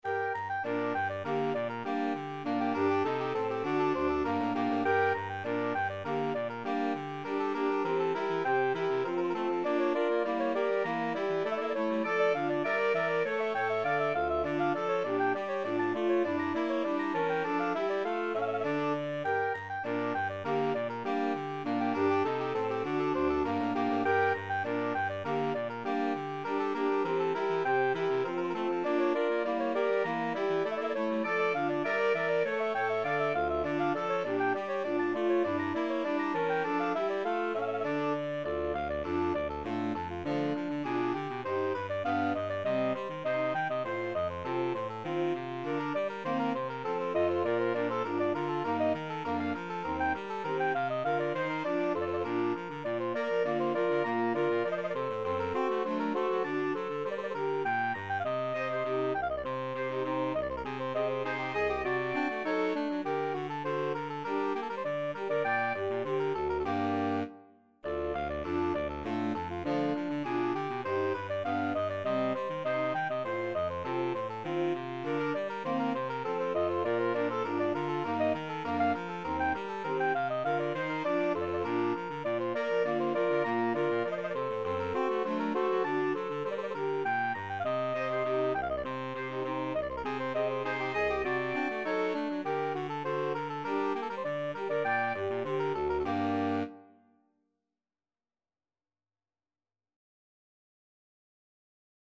Bach, Johann Sebastian - Badinerie from Orchestral Suite No.2 BWV 1067 Free Sheet music for Saxophone Quartet
Classical
Soprano Saxophone Alto Saxophone Tenor Saxophone Baritone Saxophone